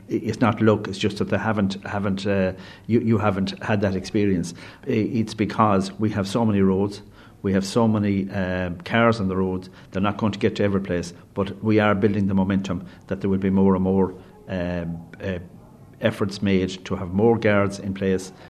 Junior Transport Minister Sean Canney, however, says Gardaí are out there, even if you don’t see them: